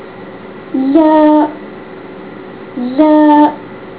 下列介绍太魯閣族基本語言的發音，並列出太魯閣語字母表及發音表。